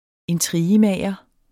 Udtale [ -ˌmæˀjʌ ]